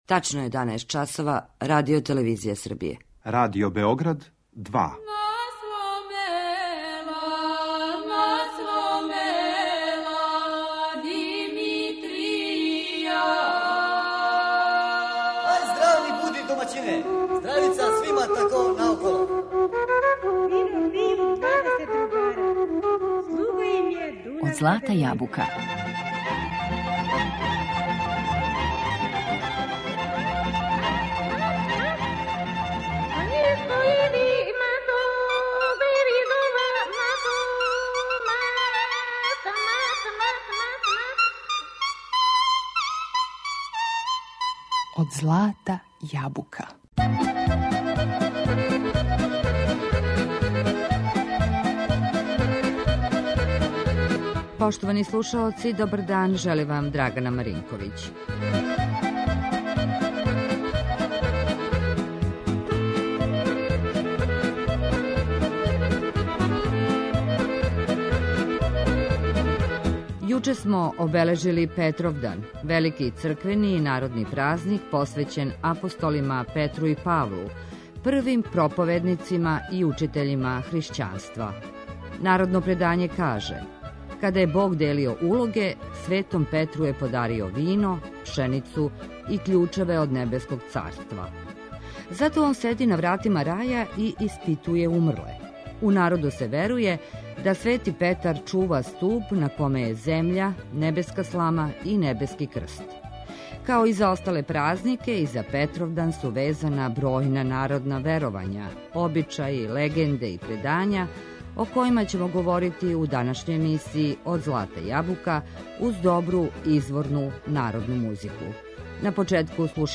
U današnjoj emisiji Od zlata jabuka govorićemo o brojnim narodnim predanjima, običajima, legendama i verovanjima vezanim za Petrovdan, uz dobru izvornu narodnu muziku .